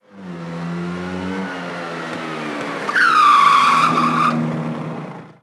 Moto marca Vespa frenazo 3
frenazo
motocicleta
Sonidos: Transportes